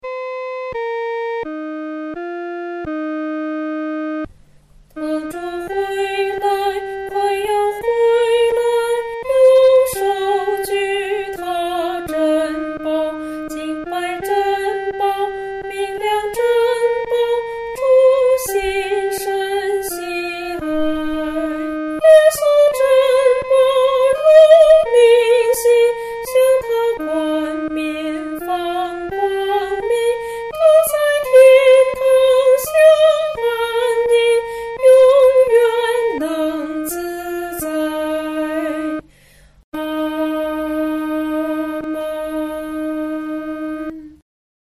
独唱（女高）